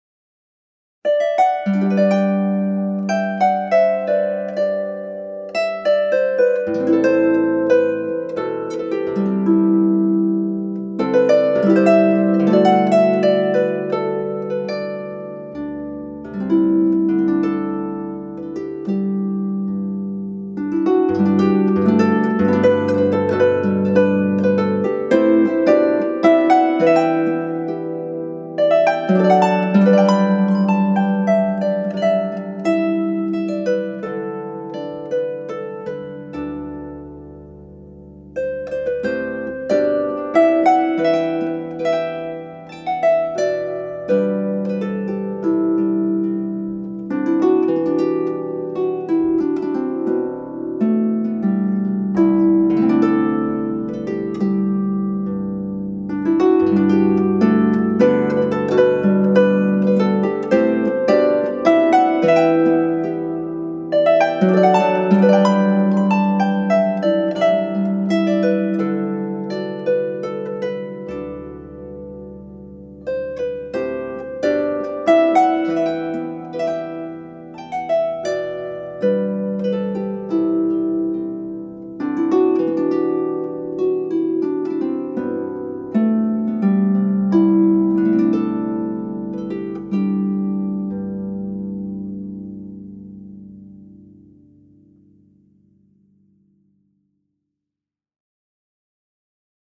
In 2016, MN made audio-visual recordings in the ITMA studio of ten of Ireland’s leading contemporary harpers giving solo performances of the 66 tunes from the first 1797 publication.